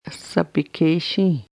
Some long vowels are pronounced with air flowing through the nose.